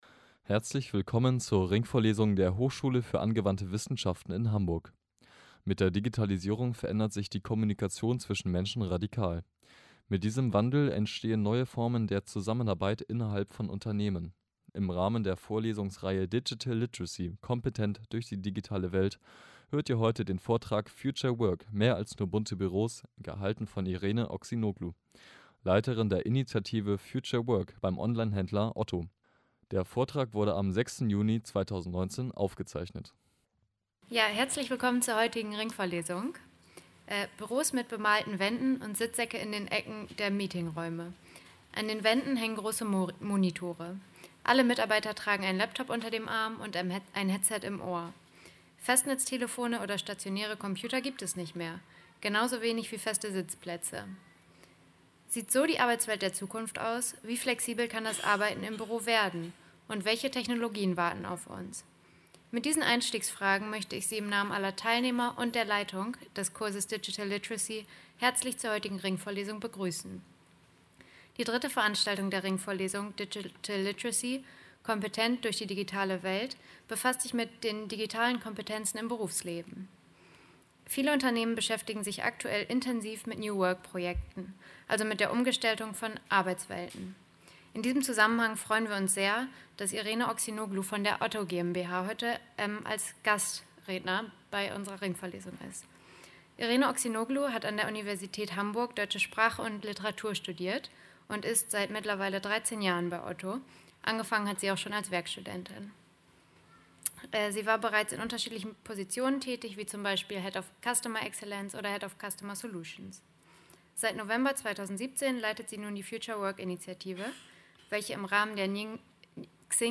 Aufgezeichneter Vortrag, der am 16. Mai 2019 gehalten wurde.